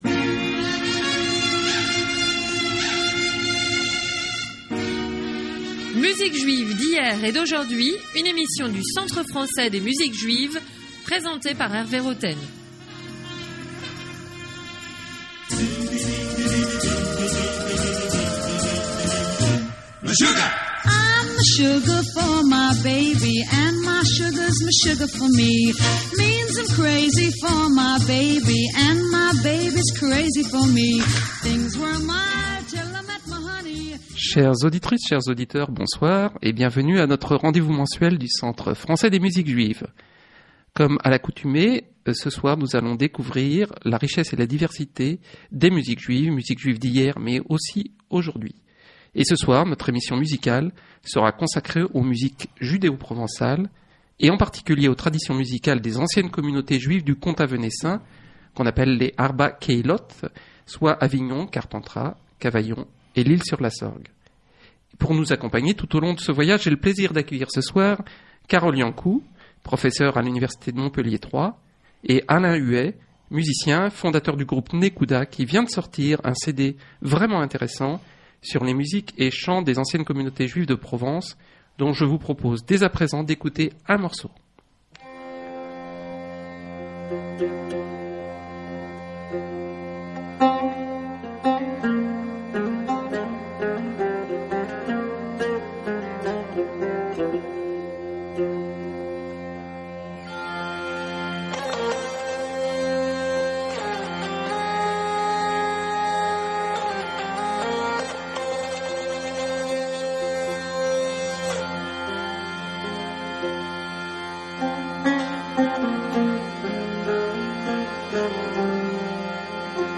MUSIQUES JUIVES D’HIER ET D’AUJOURD’HUI – TUESDAY MAY 10, 2011, JUDAÏQUES FM (94.8), 21H00. Radio program in French